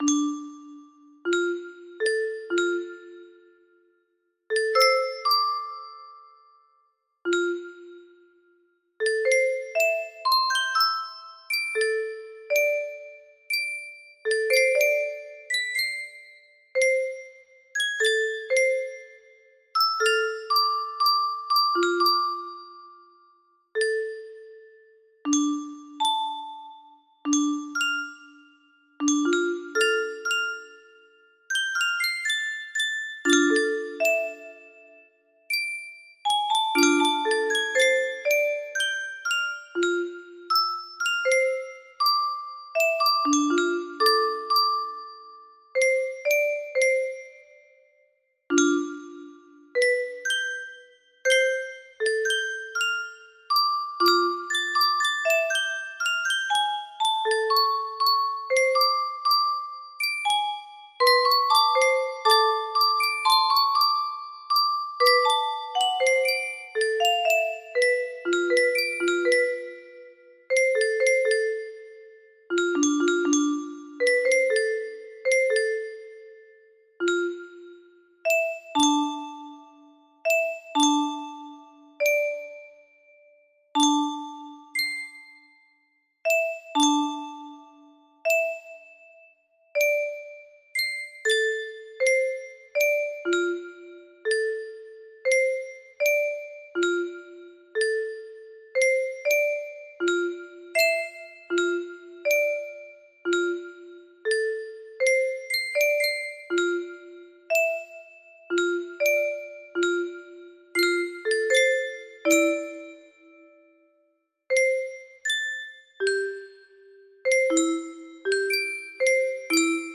The Kush Jazz music box melody
Full range 60